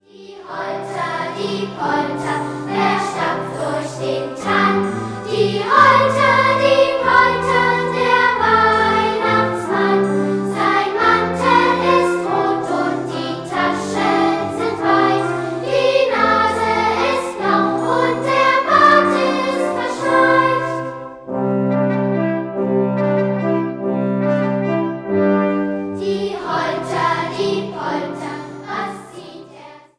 Klavier
einfühlsame Klavierbegleitung